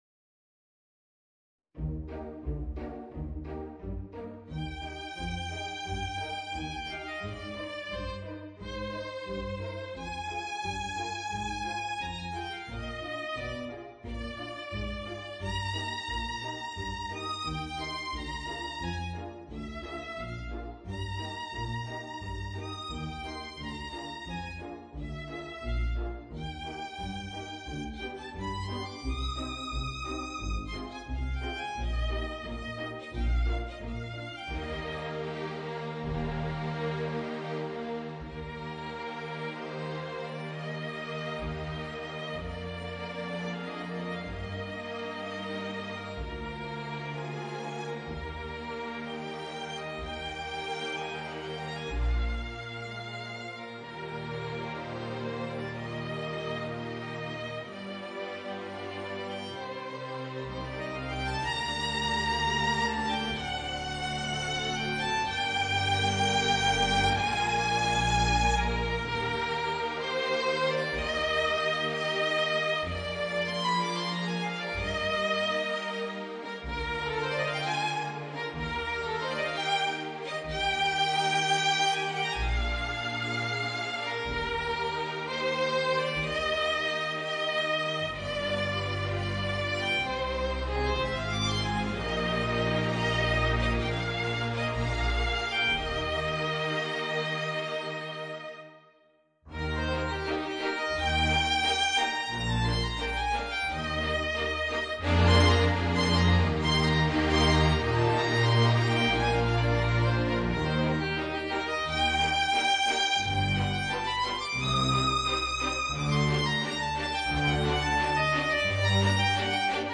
Voicing: Flute and String Quintet